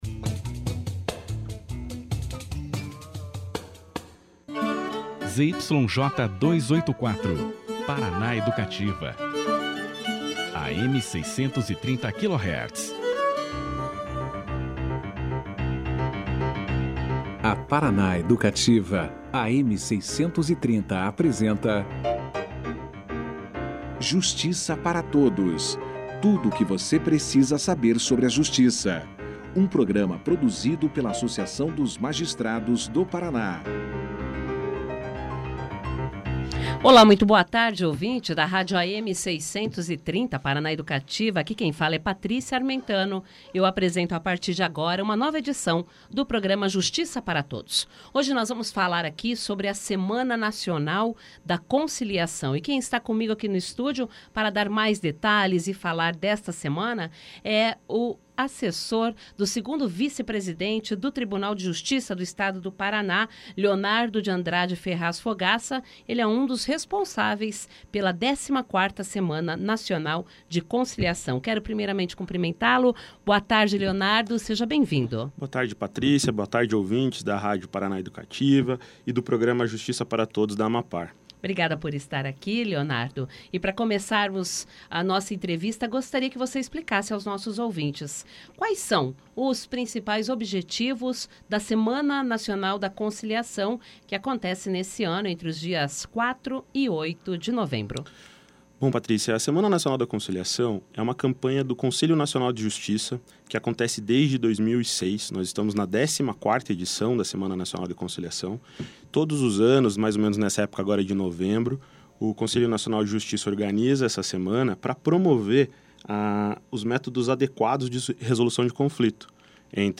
E explicou como vão acontecer as audiências pelas Comarcas no Estado, durante a semana que vem. Confira aqui a entrevista na íntegra.